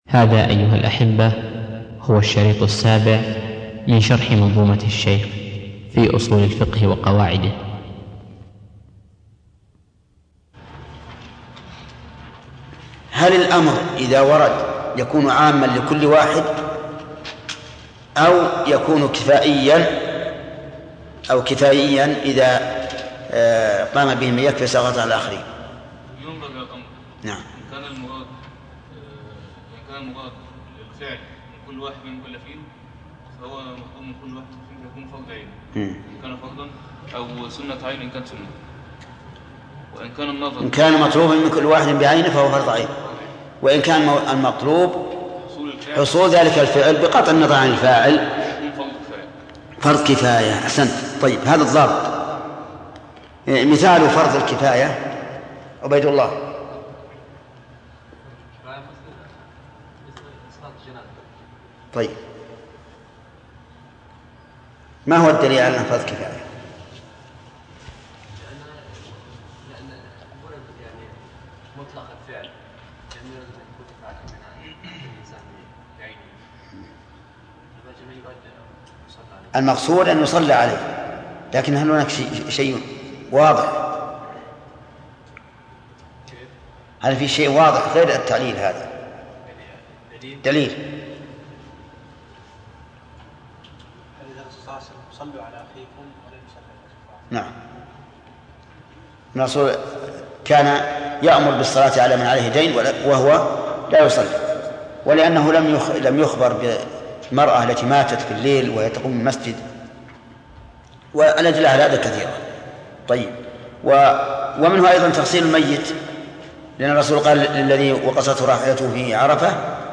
شرح كتاب المنظومة في أصو ل الفقه و قواعده - الشيخ: محمد بن صالح العثيمين
الدرس السابع